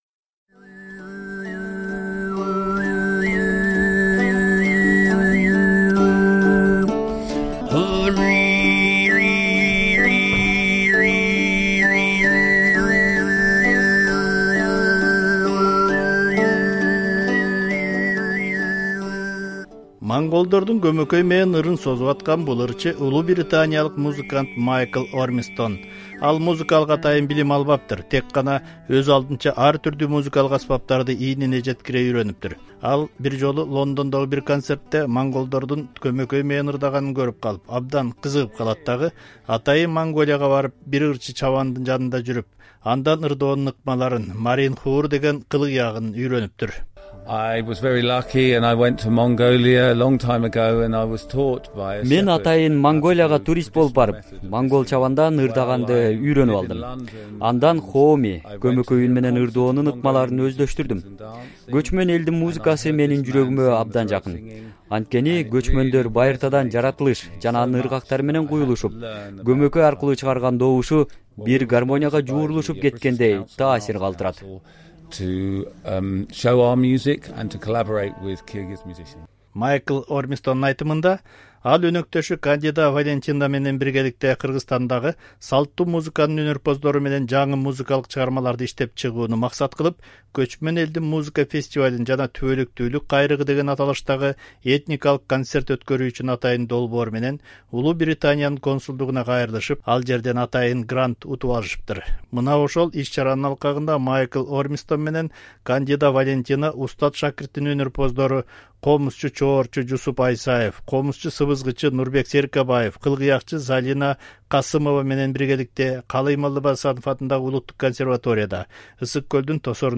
Бишкекте Монгол улуттук музыка жана Хооми көмөкөй үн менен ырдоонун чеберлери, британ музыканттары жана Кыргызстандагы "Устат-шакирт" ансамблинин өнөрпоздору биргелешип чоң программадагы концерт тартуулашты.